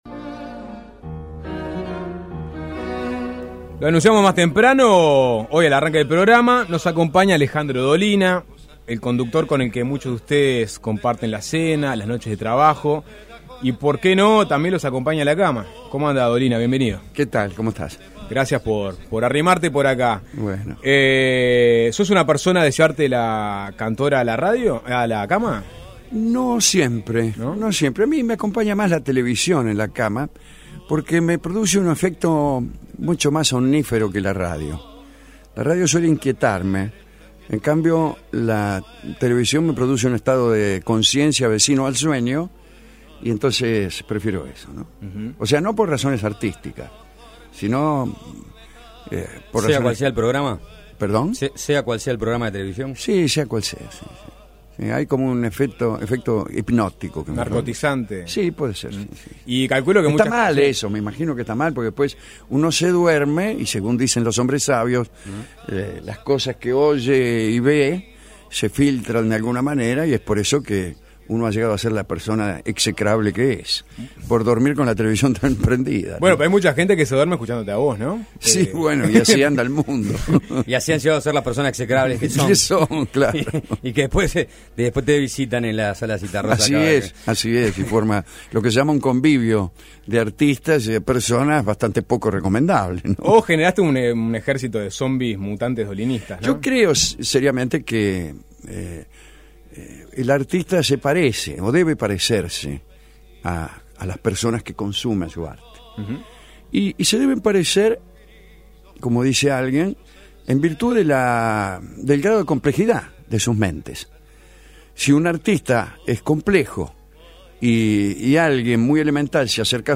Entrevista a Alejandro Dolina en Suena Tremendo